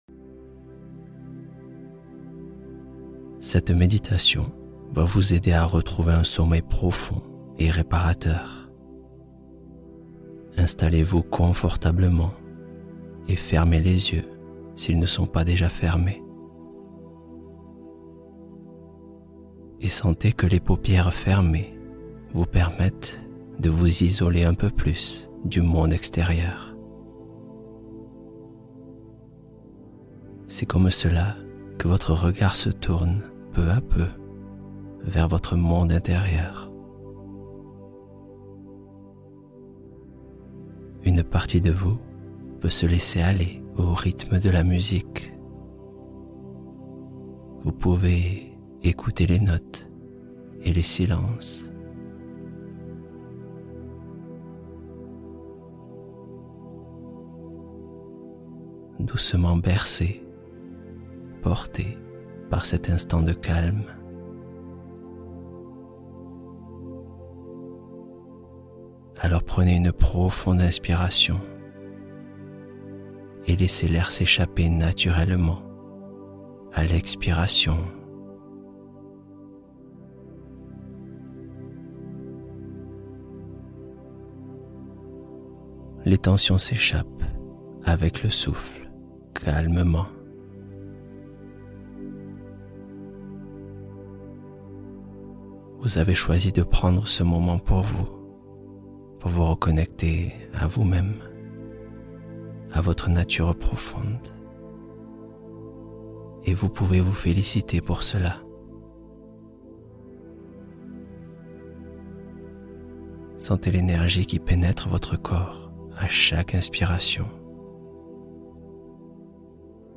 Douceur Nocturne : Méditation guidée pour un endormissement fluide